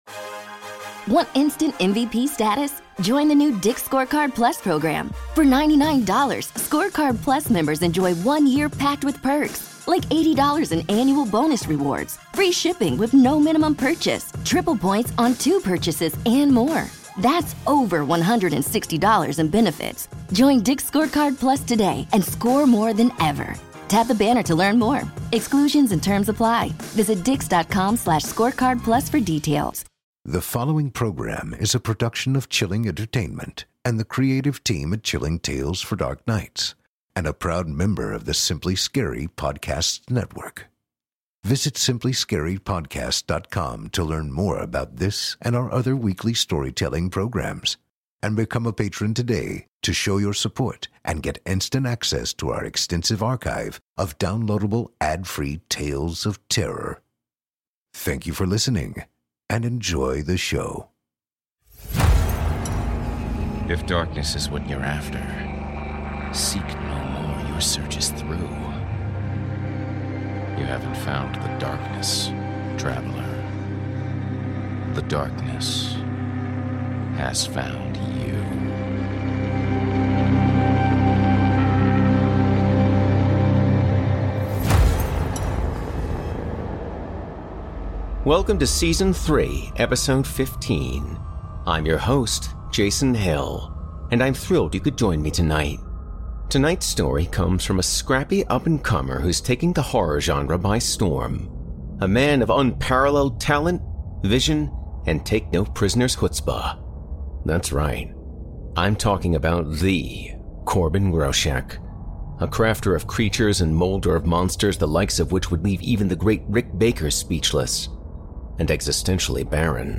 A Horror Anthology and Scary Stories Series Podcast
performed by host and narrator